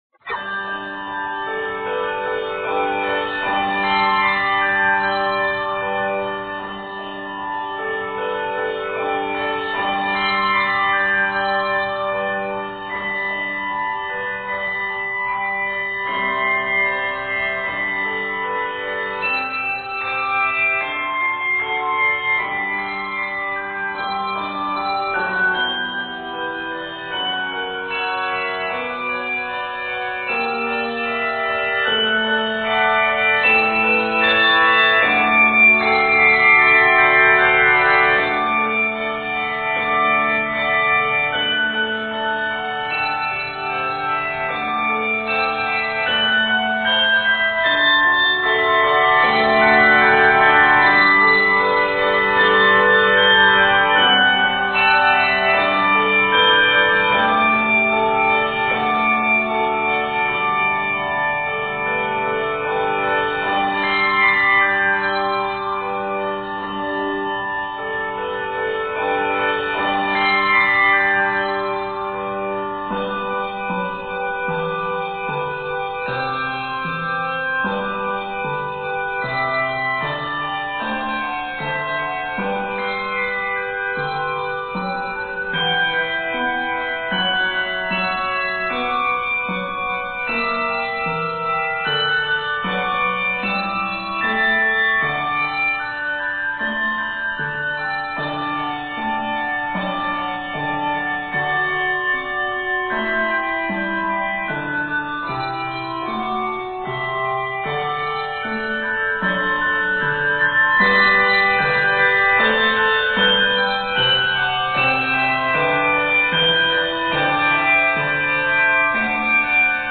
This sensitive union of tunes
Set in G Major and C Major, measures total 70.